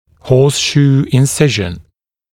[‘hɔːsʃuː ɪn’sɪʒn][‘хо:сшу: ин’сижн]подковообразный разрез